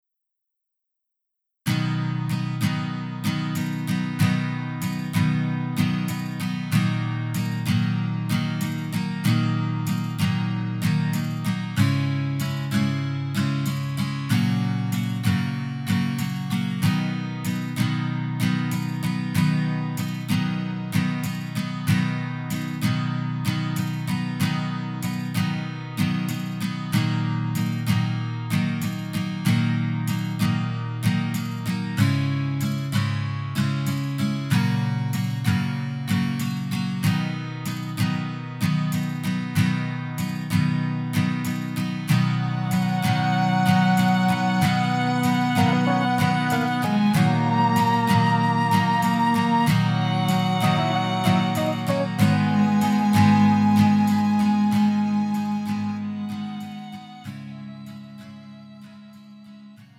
음정 -1키 2:31
장르 가요 구분 Pro MR
Pro MR은 공연, 축가, 전문 커버 등에 적합한 고음질 반주입니다.